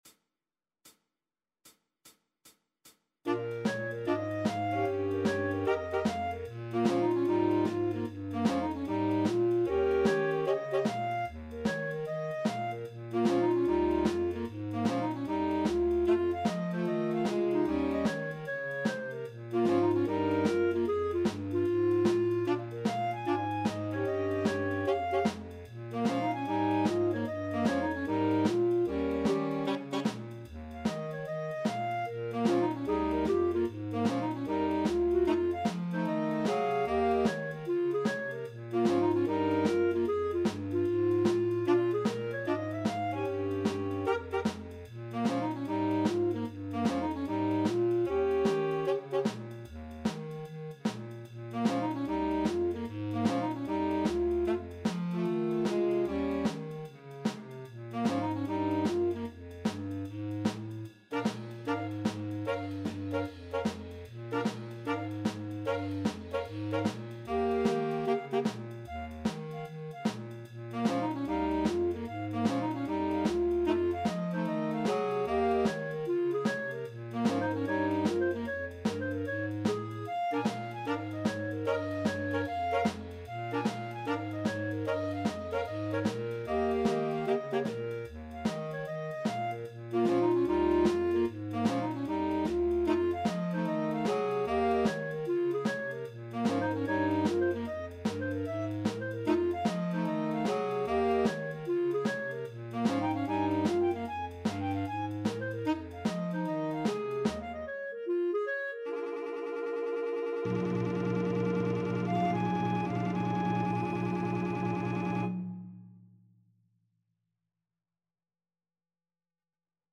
ClarinetFluteAlto Saxophone
Tenor SaxophoneFlute
Bass ClarinetBaritone Saxophone
Percussion
4/4 (View more 4/4 Music)